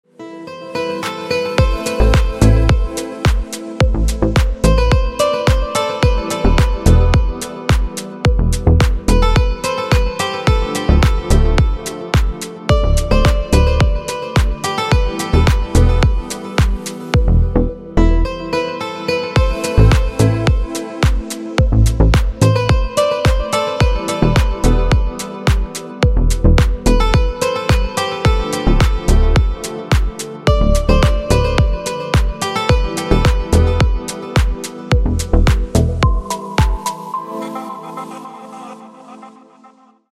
Клубные Рингтоны » # Рингтоны Без Слов
Танцевальные Рингтоны